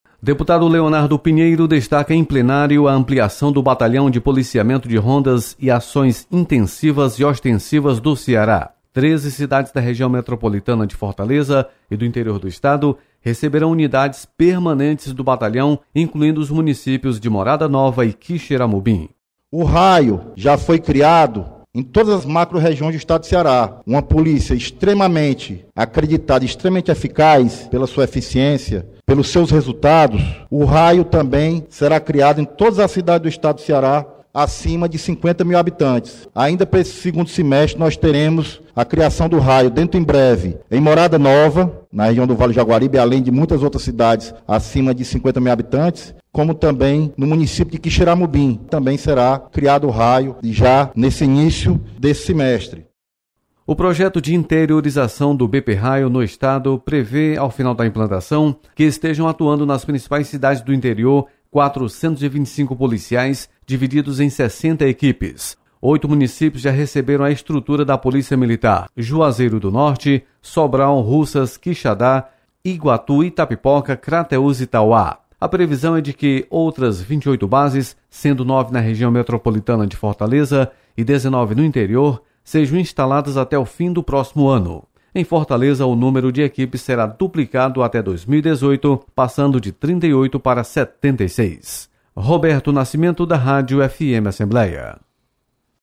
Deputado Leonardo Pinheiro destaca ampliação do Raio no interior. Repórter